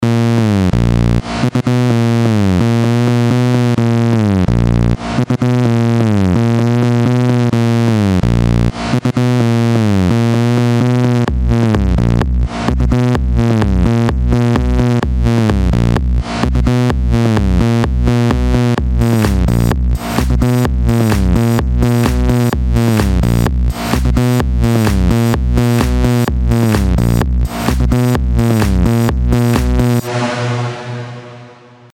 Во всех примерах звуку пилы всегда не хватает некоего дребезга в целом и пердежа на низкой ноте .
Ниже пример с переключением звука пилы с обработанного на необработанный .